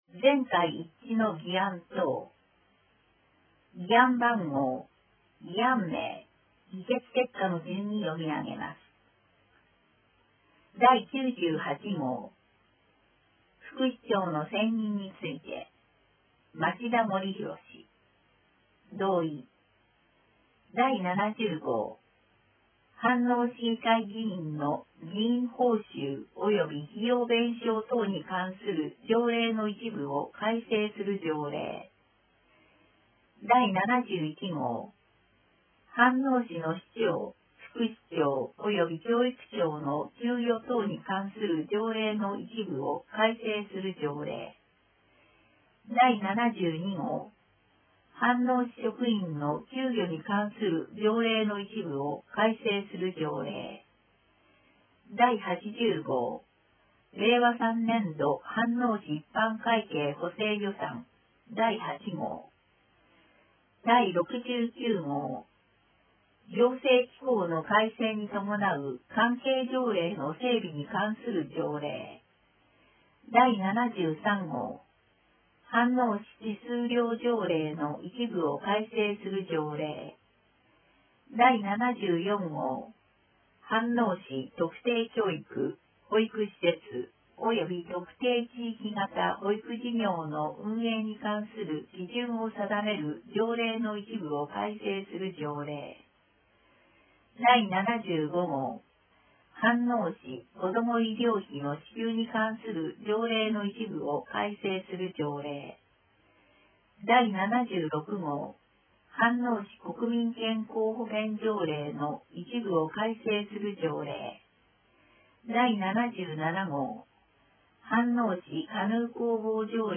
声の議会だより 第162号(mp3)